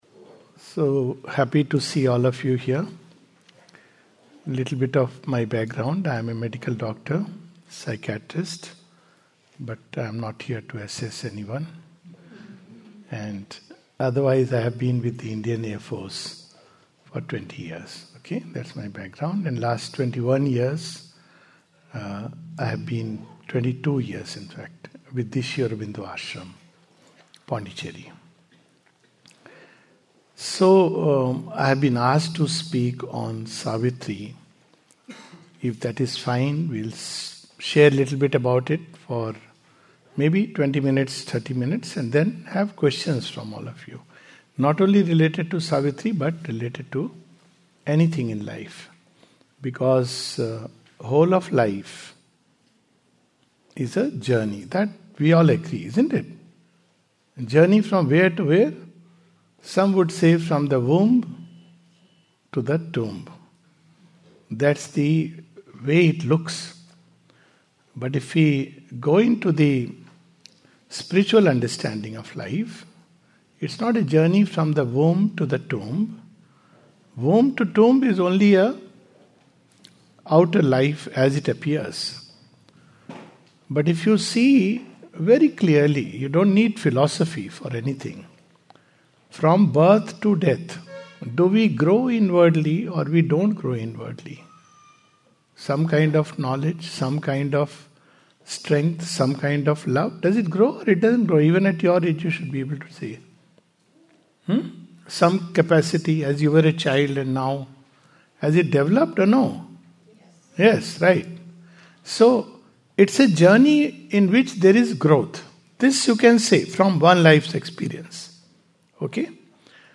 This talk has been delivered at Auroville as part of a Youth program on Jan. 23, 2026.